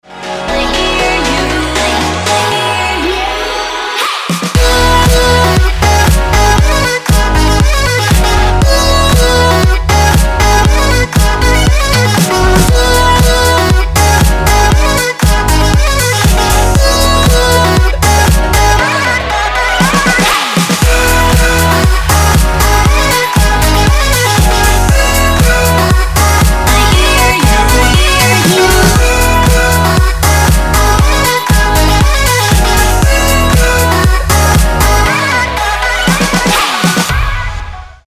• Качество: 320, Stereo
dance
Electronic
house